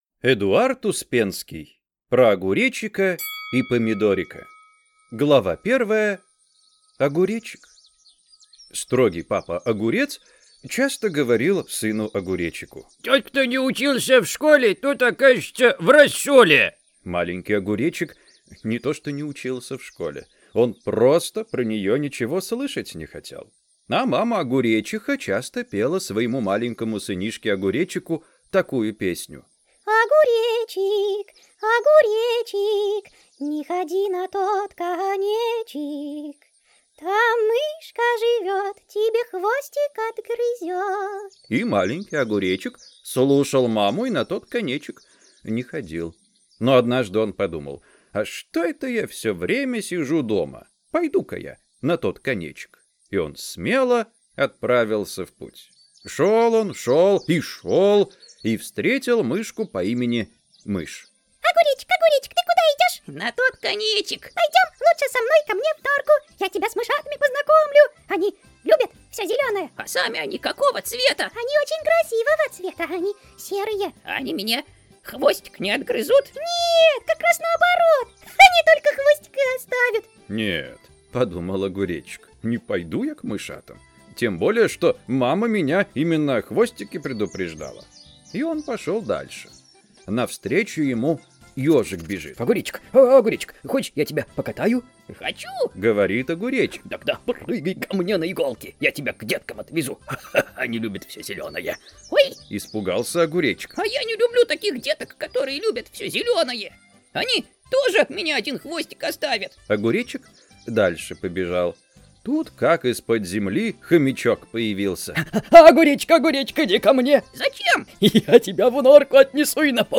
Аудиокнига Про Огуречика и Помидорика | Библиотека аудиокниг